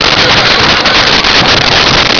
Sfx Crash Metal Scrape
sfx_crash_metal_scrape.wav